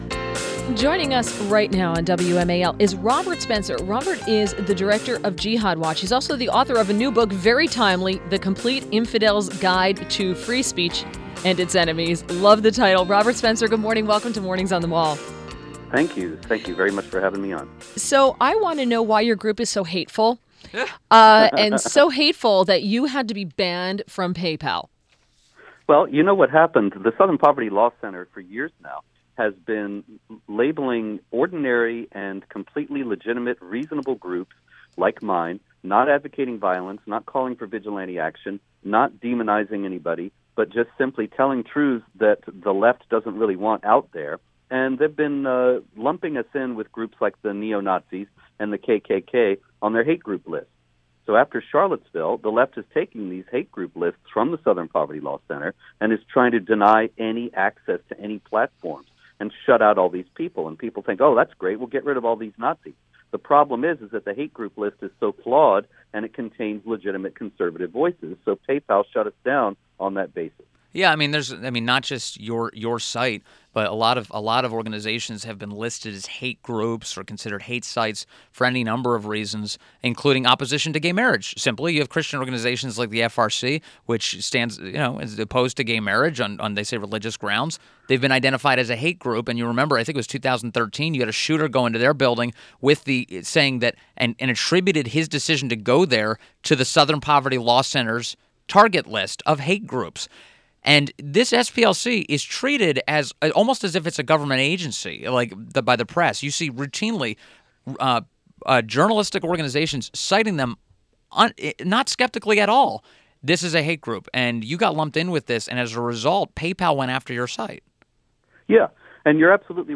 WMAL Interview - ROBERT SPENCER 08.24.17